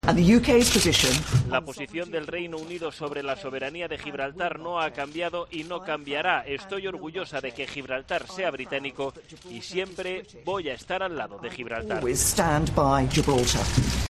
Theresa May, que ha comparecido ante la prensa, ha subrayado que el Reino Unido "no cambia el compromiso" del país de negociar una relación futura que "funcione" "para toda la familia del Reino Unido, incluido Gibraltar".